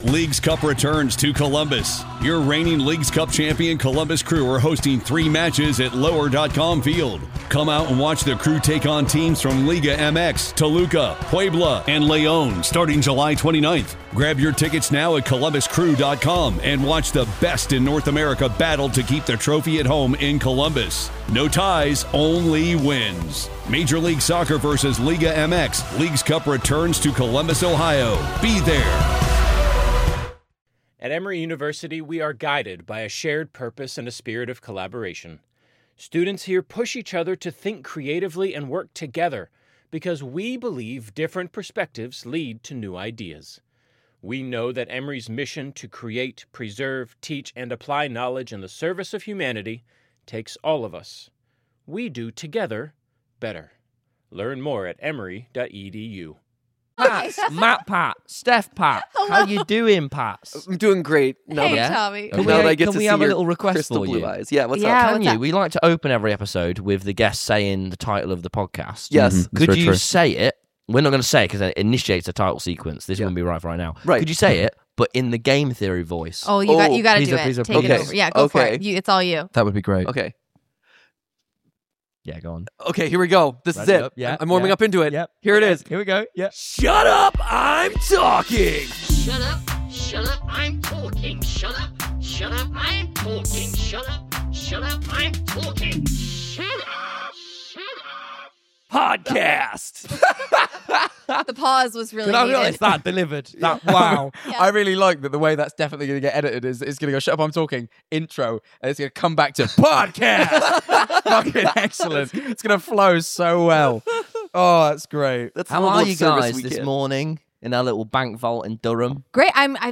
YouTube legends MatPat and Stephanie join us for a post-retirement interview. They share personal insights into Game Theory, musical theatre dreams, and running a business, along with advice on finding yourself and staying true to who you are.
This episode was recorded on 13/05/25 in Durham